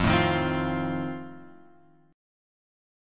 F7 chord {1 0 1 x 1 1} chord